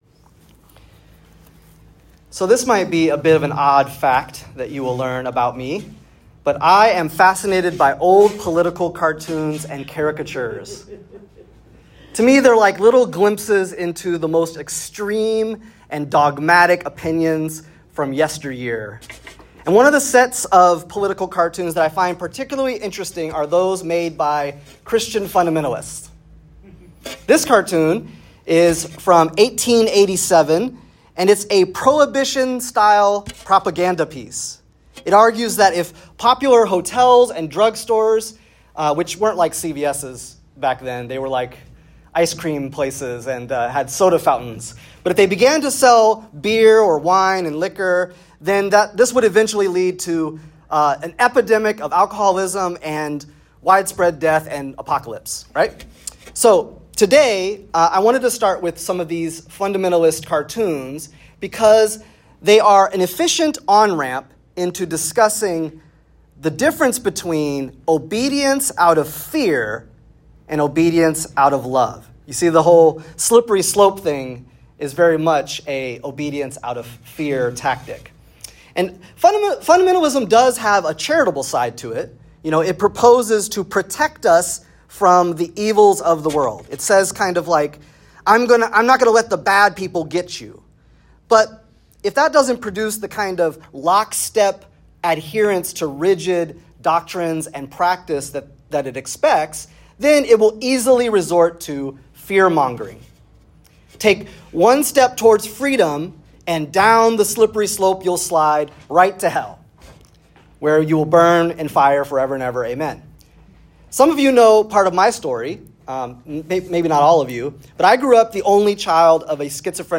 Save Audio In this sermon